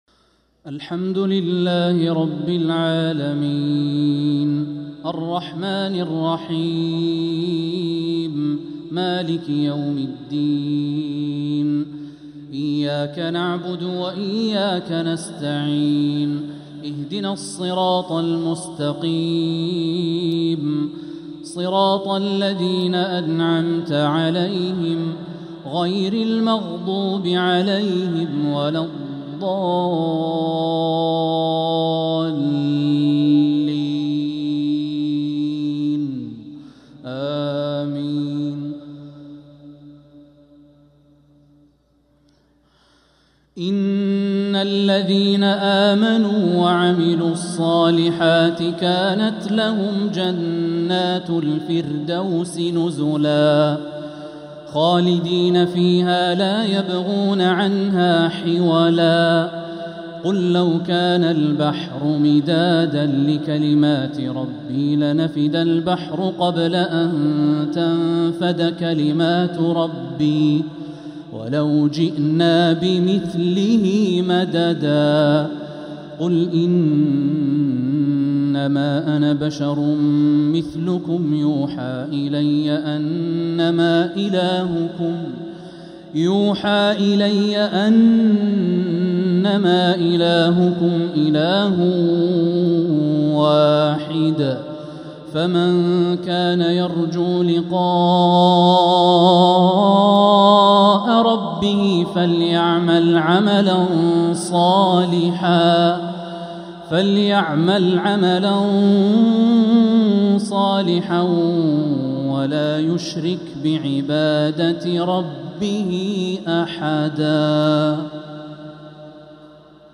مغرب الخميس 2-7-1446هـ خواتيم سورتي الكهف 107-110 و مريم 96-98 | Maghrib prayer from Surat al-Kahf & Maryam 2-1-2025 🎙 > 1446 🕋 > الفروض - تلاوات الحرمين